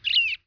bird1.wav